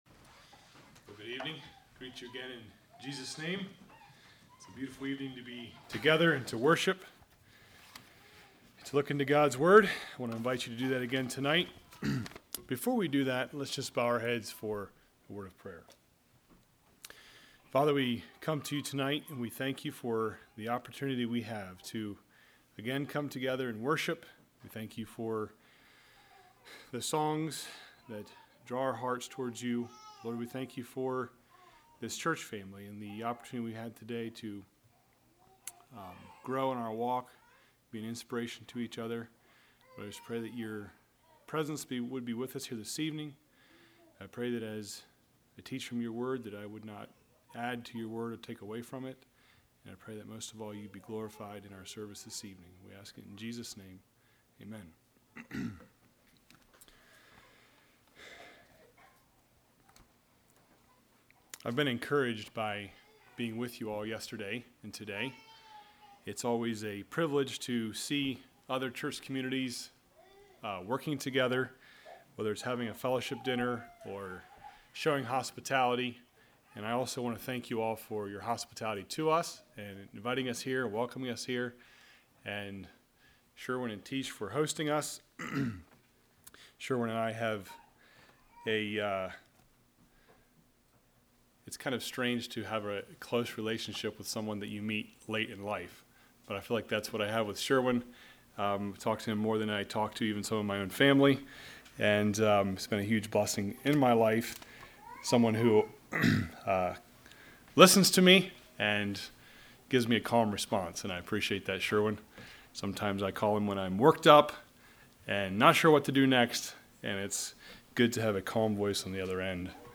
Sermons
Winchester | Bible Conference 2025